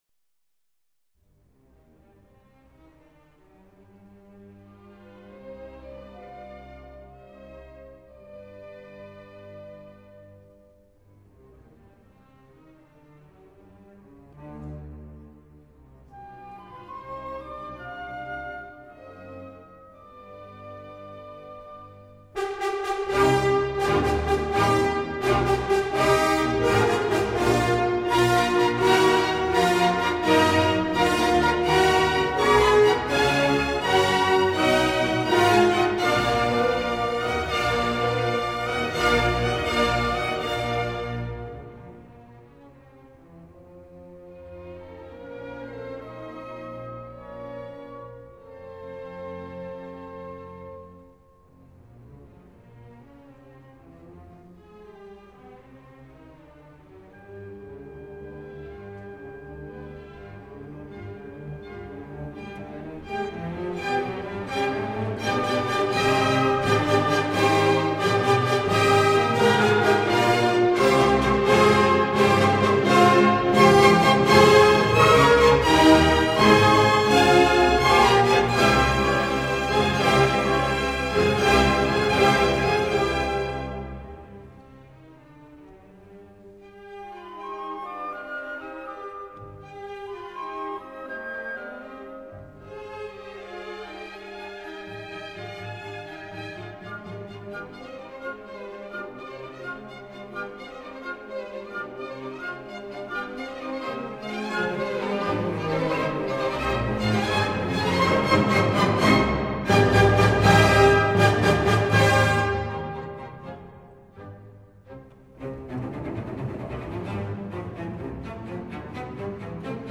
第一乐章具有强烈的节奏冲击。
一切由它控制着——有时咆哮，有时轻若耳语，有时在乐队的深处悸动，几乎听不见。
它用极大的节奏动力来结束这个乐章。